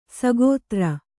♪ sagōtra